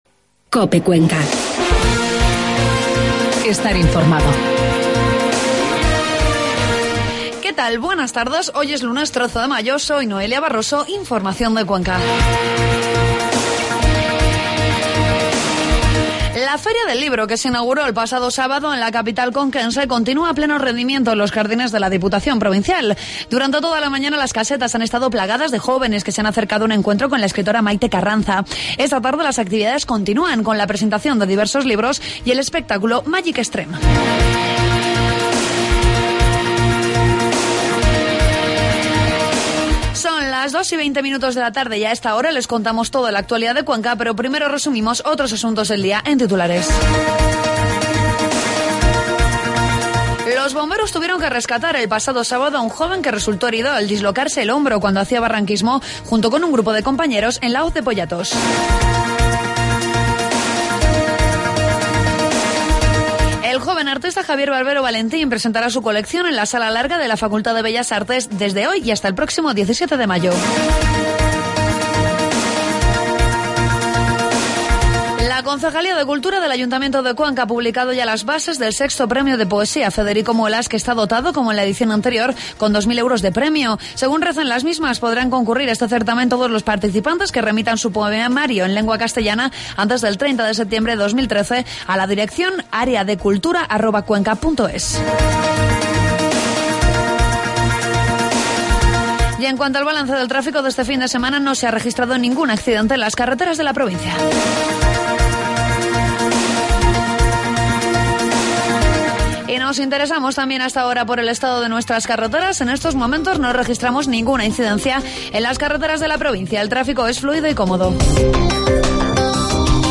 informativos de mediodía